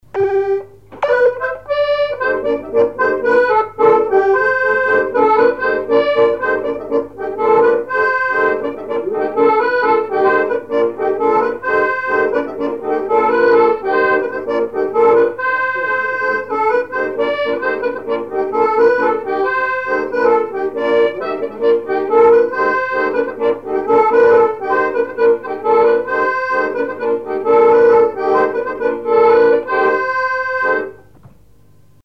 Couplets à danser
branle : courante, maraîchine
instrumentaux à l'accordéon diatonique